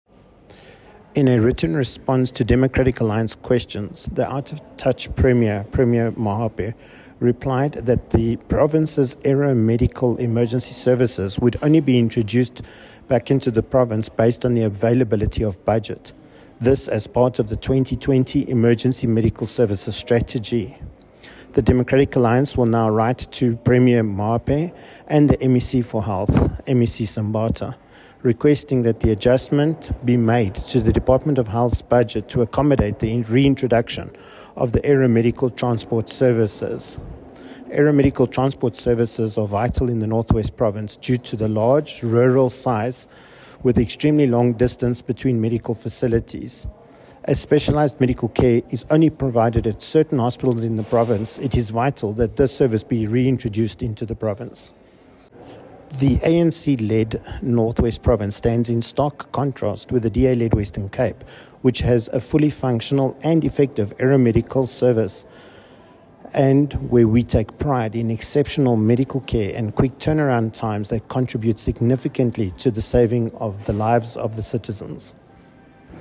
Note to Broadcasters: Please find a linked soundbite in
English by Gavin Edwards MPL.